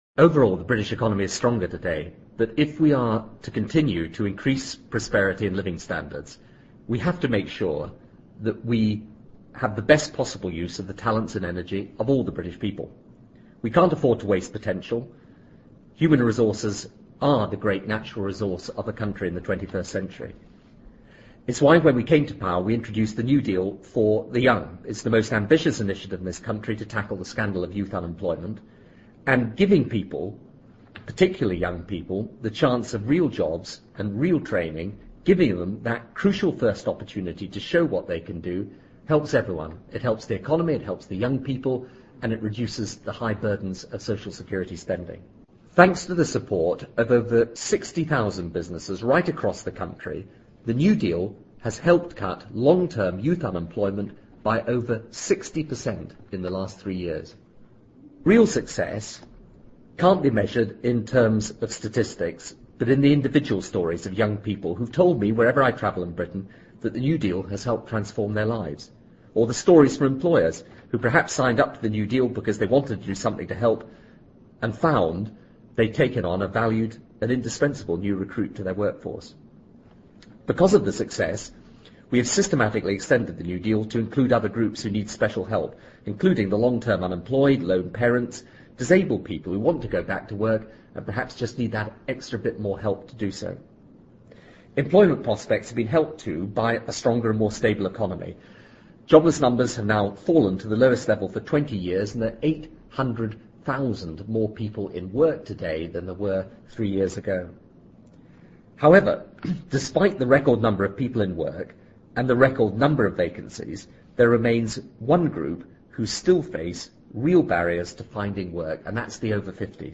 布莱尔首相演讲:New Deal for over-50s 听力文件下载—在线英语听力室